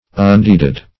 Meaning of undeeded. undeeded synonyms, pronunciation, spelling and more from Free Dictionary.
Search Result for " undeeded" : The Collaborative International Dictionary of English v.0.48: Undeeded \Un*deed"ed\, a. 1.